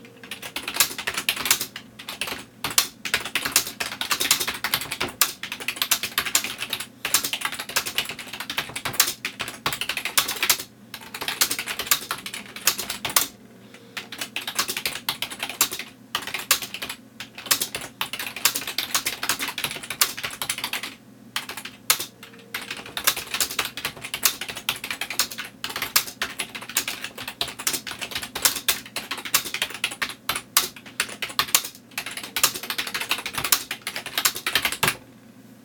typing_hyperactive
fast hyperactive keyboard PC touch-typing typing sound effect free sound royalty free Memes